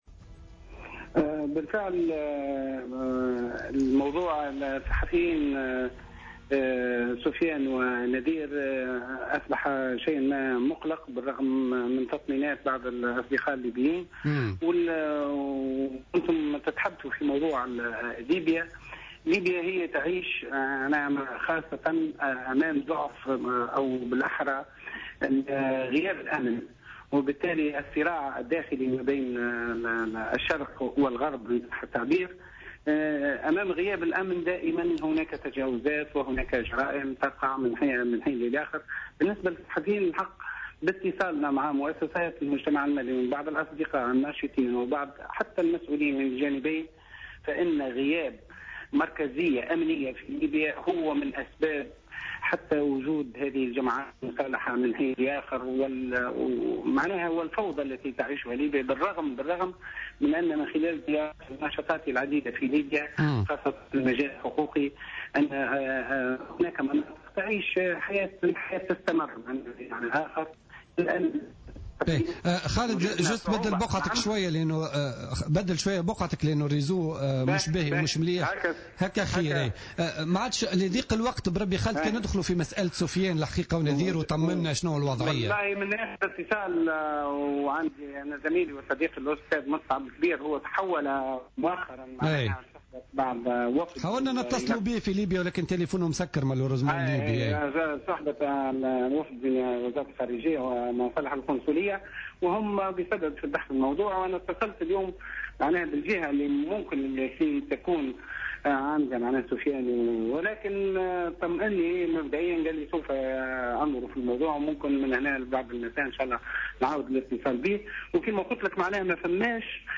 في مداخلة له في برنامج بوليتيكا